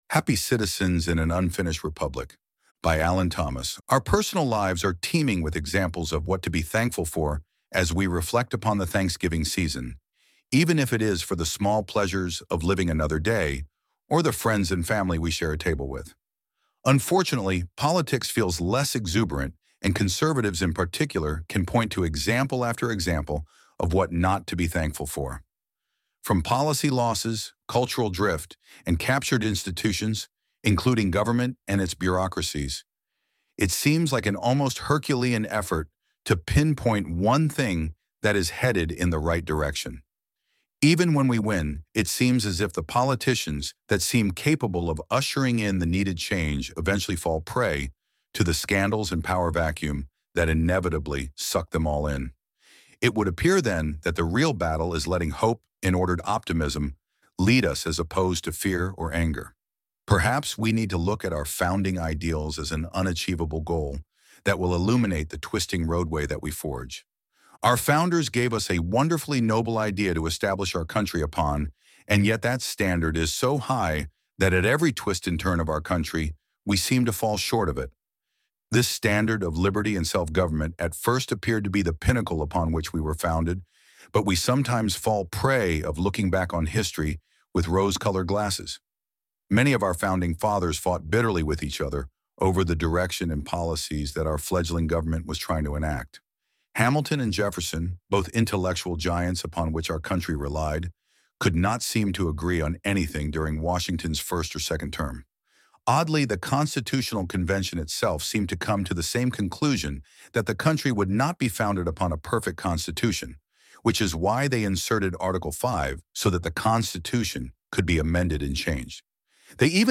Genres: News, News Commentary, Politics